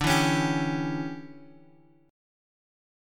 DM7sus2sus4 chord